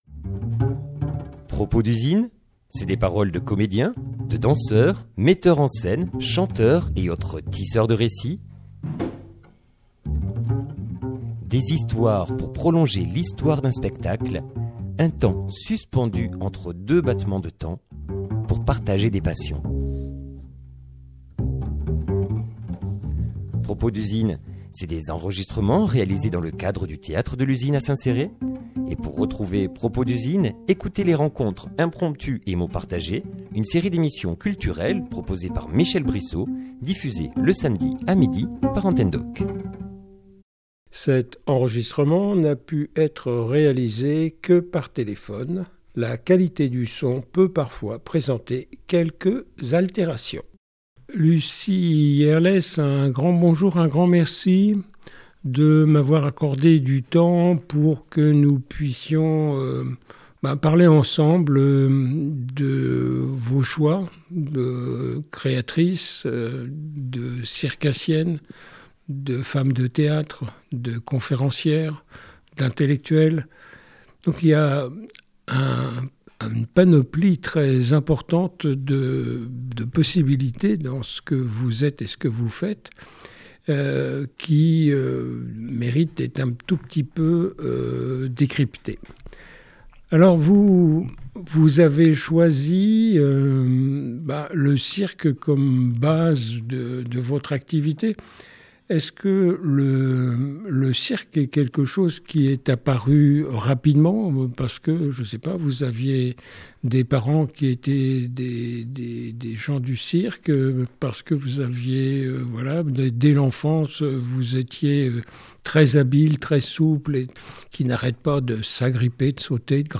Illustration sonore : Best of jazz